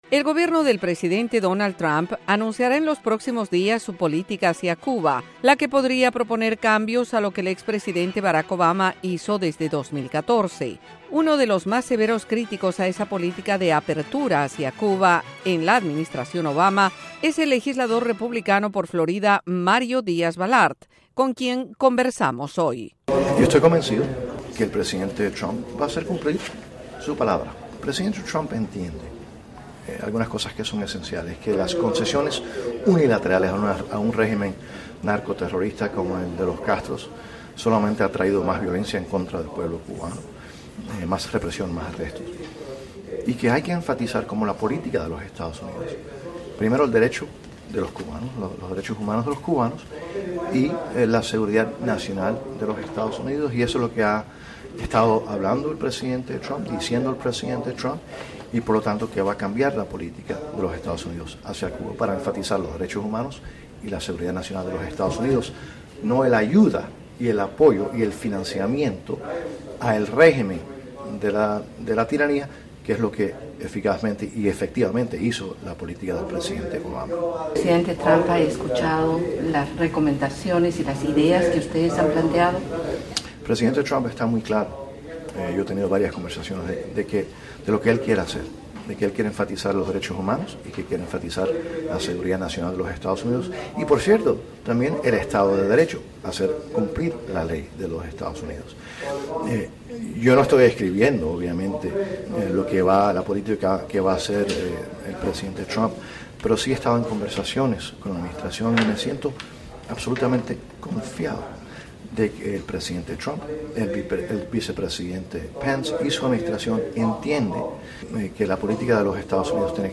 El legislador republicano por Florida, Mario Díaz Balart, afirmó en entrevista con la Voz de América que el concepto que se observa en el gobierno del presidente Donald Trump está basado en dos temas principales.
El legislador Mario Díaz Balart dialoga sobre Cuba y las futuras medidas del gobierno Trump